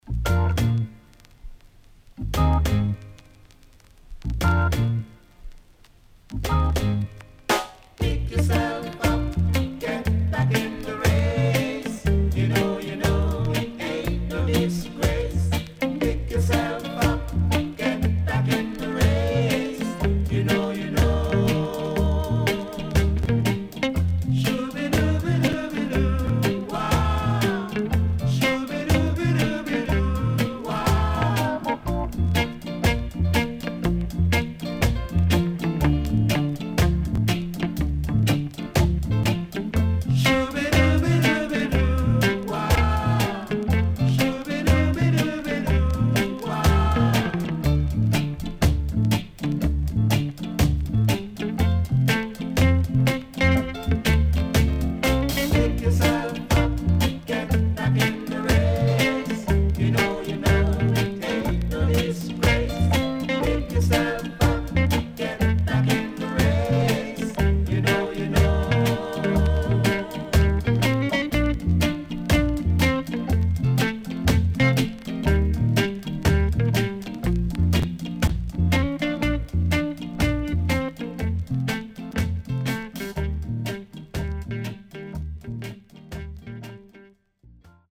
HOME > REGGAE / ROOTS  >  SWEET REGGAE
W-Side Good Duet
SIDE A:少しチリノイズ、プチノイズ入ります。